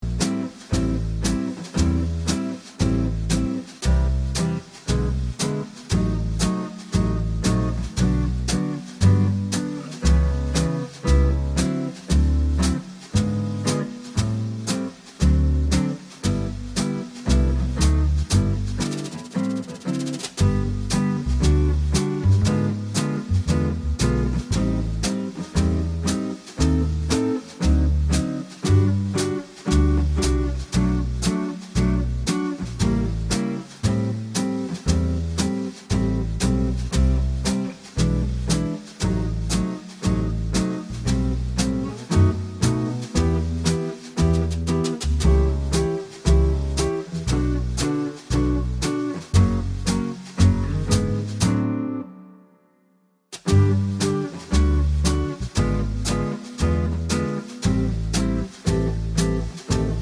Minus Vibraphone Easy Listening 2:31 Buy £1.50